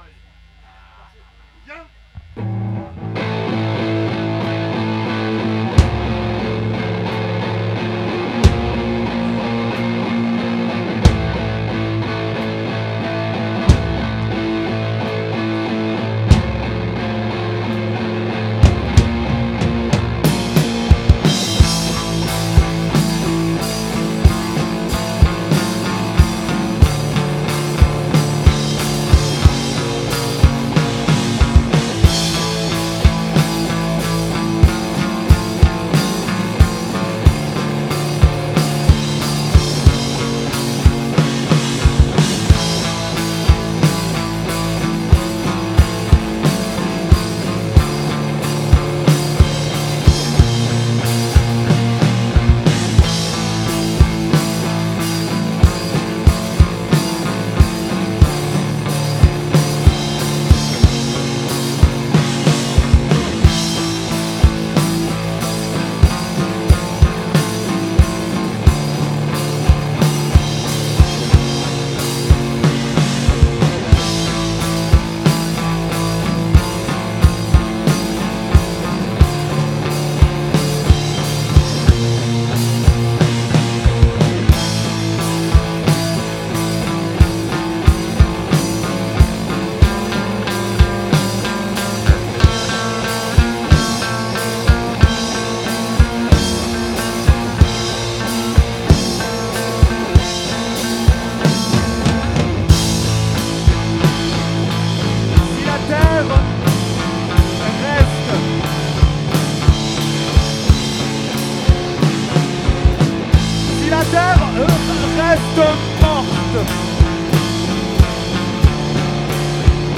sombre meta-metal de l’abîme joyeux